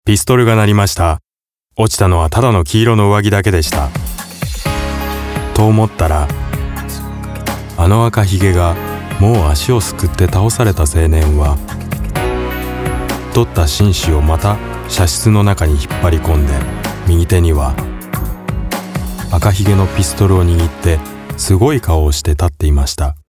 japanischer Profi Sprecher.
Japanese voice over.
Sprecher japanisch.